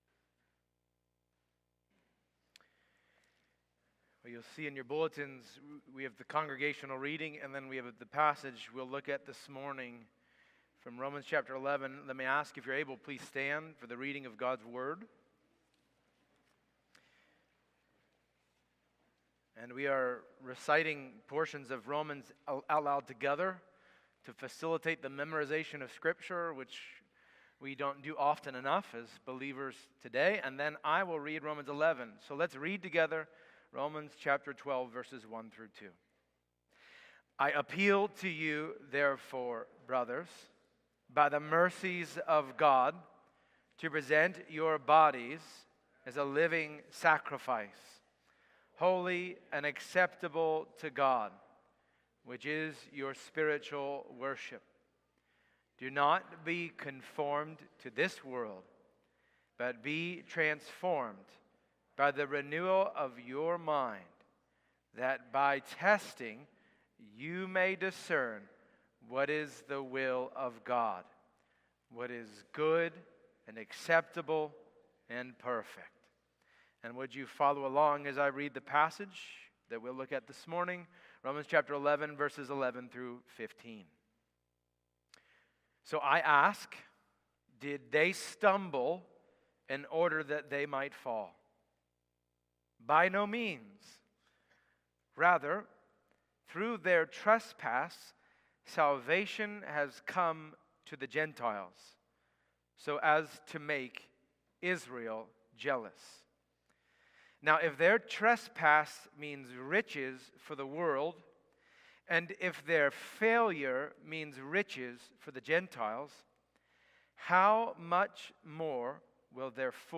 Jan-19-Worship-Service.mp3